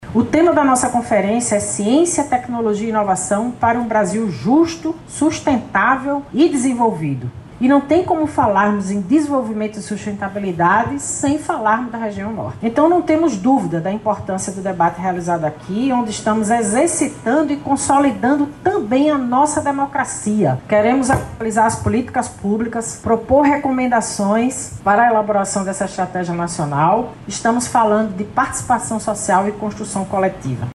Nesta quinta-feira, 18/04, foi realizada a abertura da 5ª Conferência Regional Norte de Ciência, Tecnologia e Inovação, no auditório da academia STEM da Escola Superior de Tecnologia da Universidade do Estado do Amazonas (EST/UEA).
Este evento é uma etapa de preparação para a 5ª Conferência Nacional, que marca um retorno após 14 anos, fortalecendo o panorama nacional das pesquisas e descobertas, como explica a ministra da ciência, Luciana Santos.
SONORA-1-LUCIANA-SANTOS-.mp3